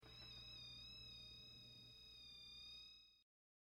The Black Hole FX - Maximillian's pulsating eye
The_Black_Hole_FX_-_Maximillian_s_pulsating_eye.mp3